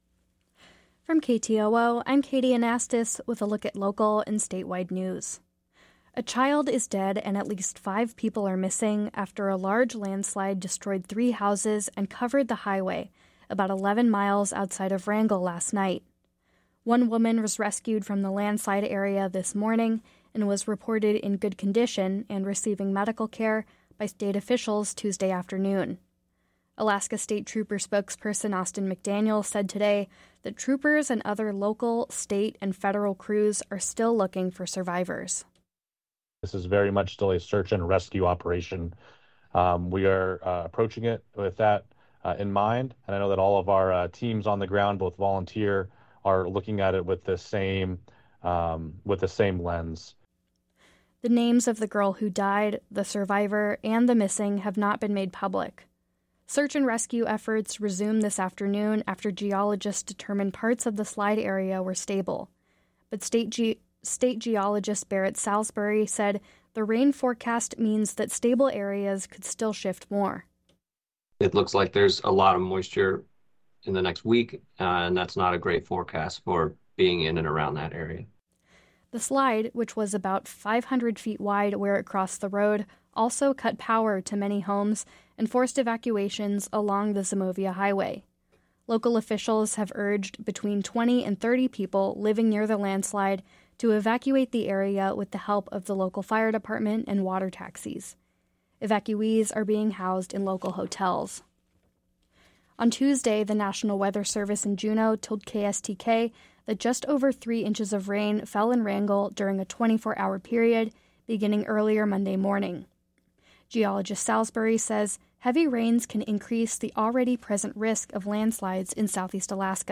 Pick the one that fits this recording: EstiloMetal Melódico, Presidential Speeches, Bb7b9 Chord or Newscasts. Newscasts